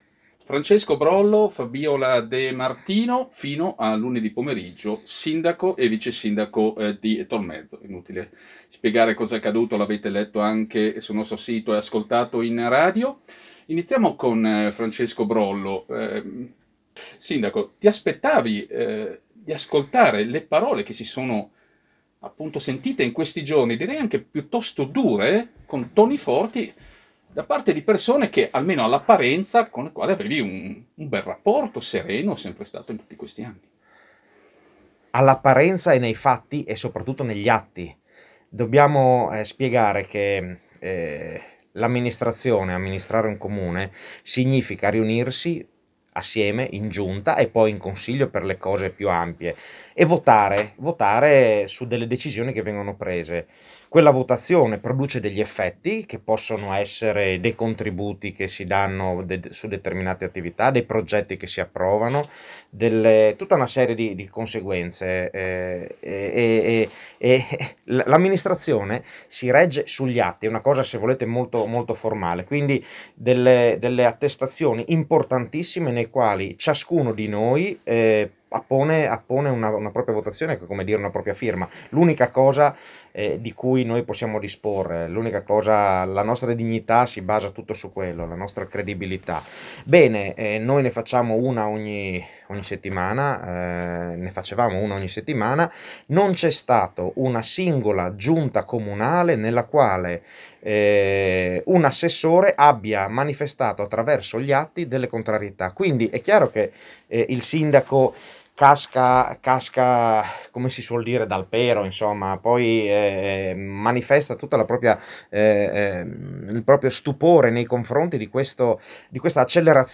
L'ex sindaco e l'ex vicesindaco ospiti nella redazione di Studio Nord News
Oggi nella redazione di Studio Nord News sono stati ospiti l’ex sindaco di Tolmezzo Francesco Brollo e l’ex vicesindaco Fabiola De Martino. Tema, naturalmente, lo scioglimento del Consiglio Comunale.